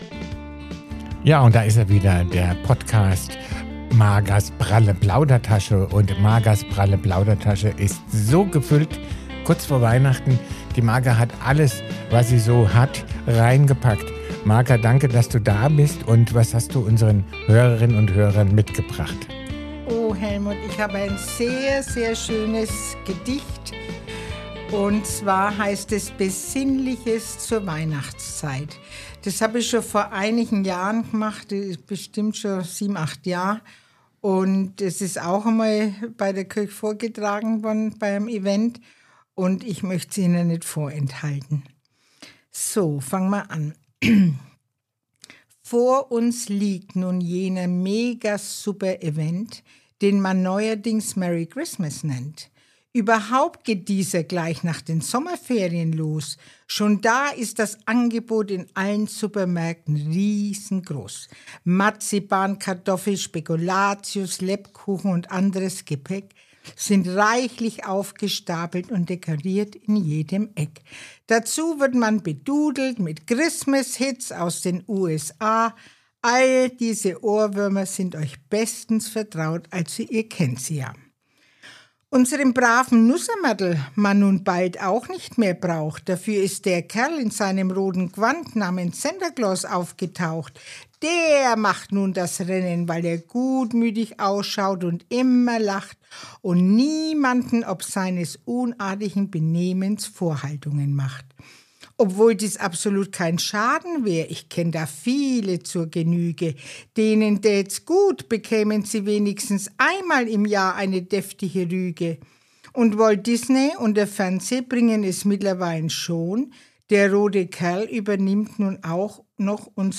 Ein Gedicht über Kindheitserinnerungen, Konsum und echte Weihnachtsfreude. Lauscht den Gedanken zwischen Nostalgie und Gegenwart.